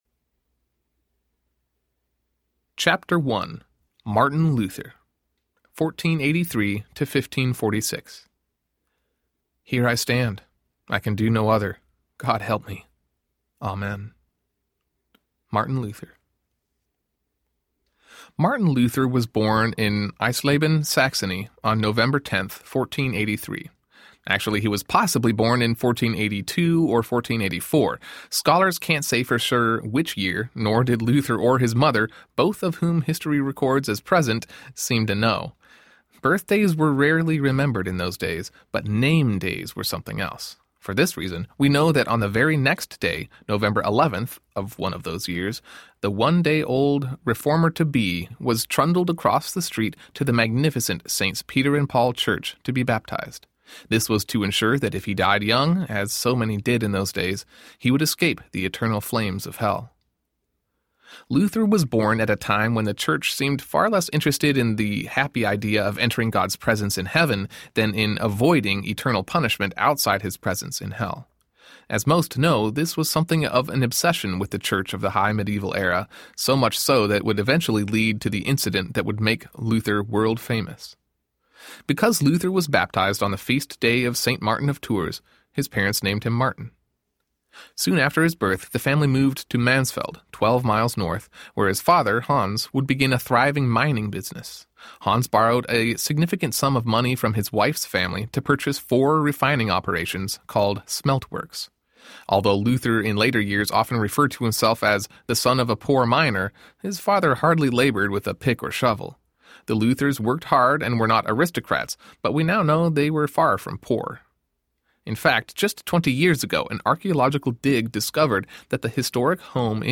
Seven More Men Audiobook
Narrator